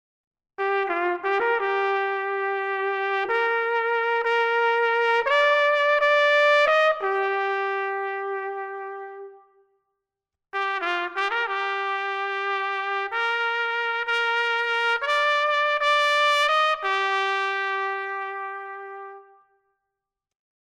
Bugel - Muziek & Meer - HaFa
Je hoort zo twee blaasinstrumenten: de bugel en de trompet.